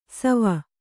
♪ sava